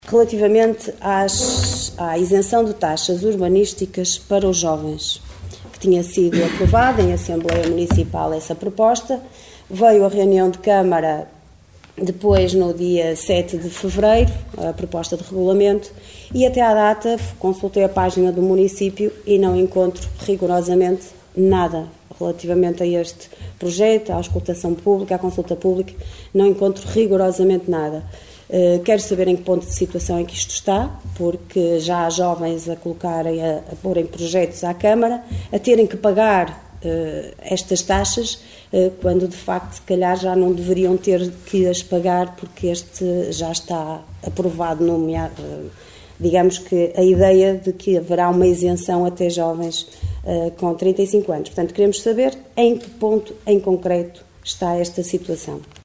O reparo foi feito no período antes da ordem do dia da última reunião do executivo pela vereadora da OCP Liliana Silva, que comparou esta isenção com a taxa turística que a Câmara quer implementar e que já foi publicada em Diário da República.